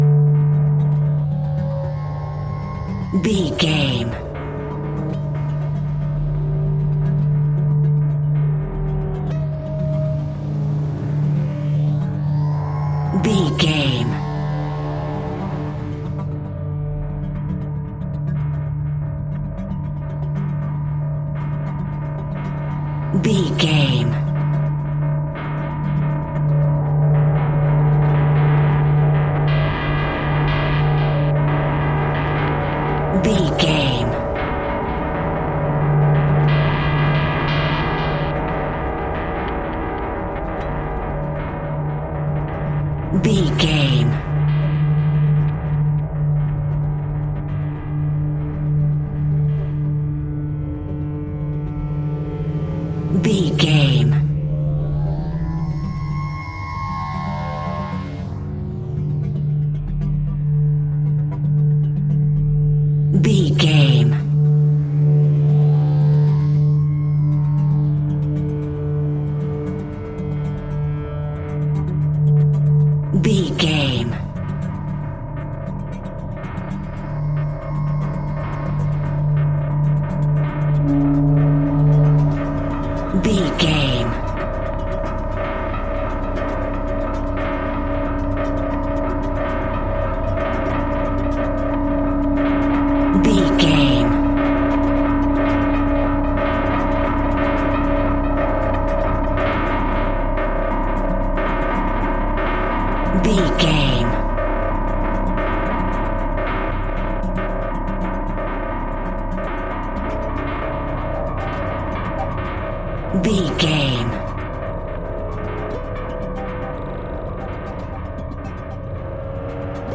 Atonal
D
Slow
tension
ominous
suspense
eerie
synthesiser
Horror Ambience
Synth Pads
Synth Ambience